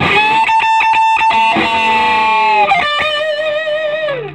Index of /90_sSampleCDs/USB Soundscan vol.22 - Vintage Blues Guitar [AKAI] 1CD/Partition C/04-SOLO D125